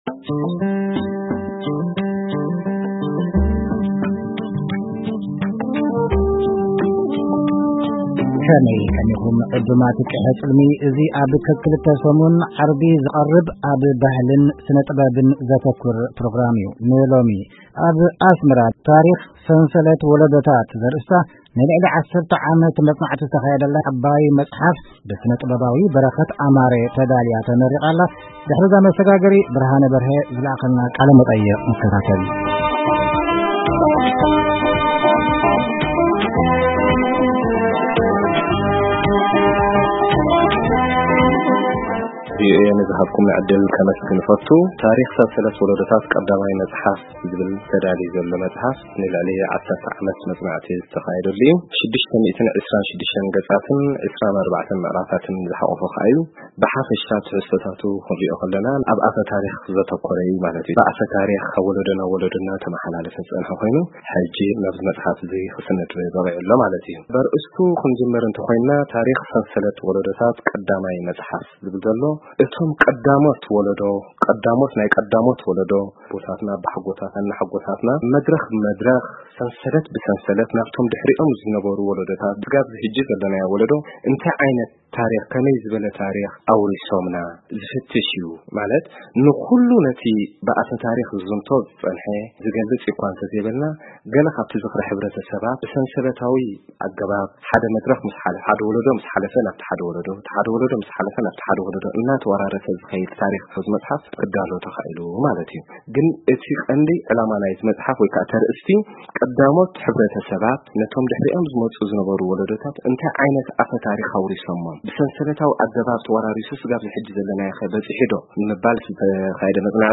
ዕላል